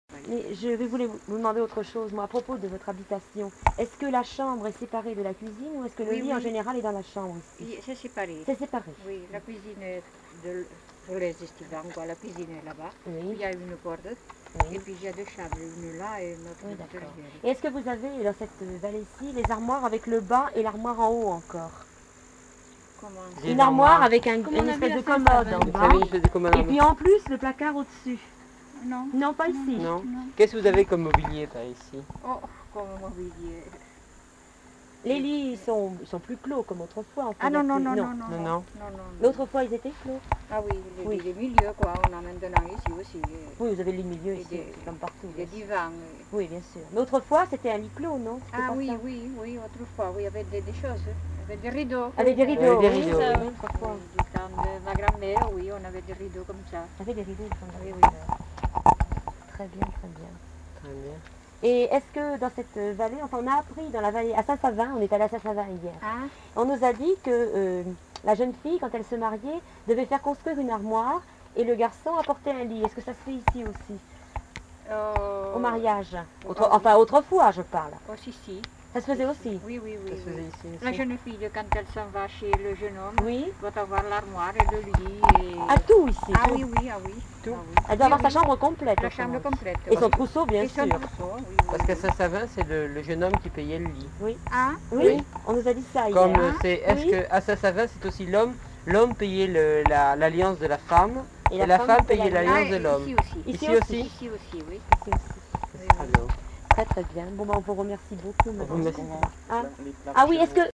Aire culturelle : Bigorre
Genre : témoignage thématique
Notes consultables : L'informatrice n'est pas identifiée.